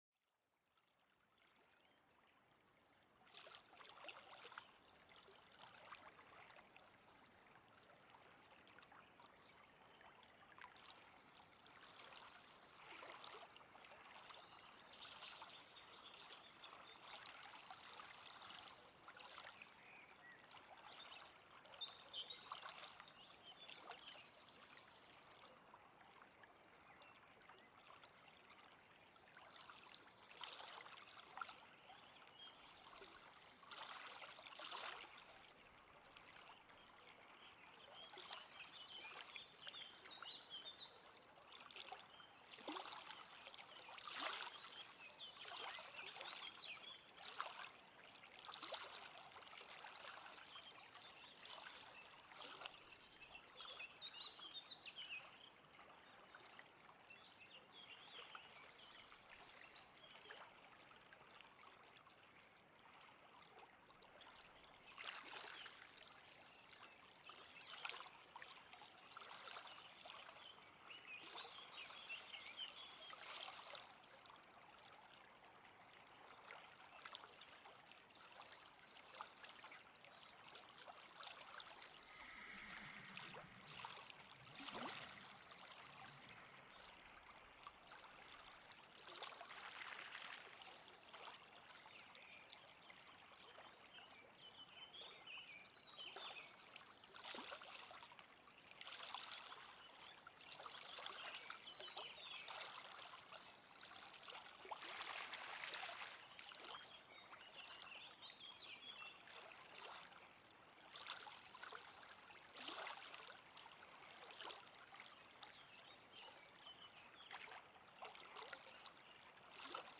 Bergfluss-Frühlingsenergie: Wasser schenkt neue entspannende Kraft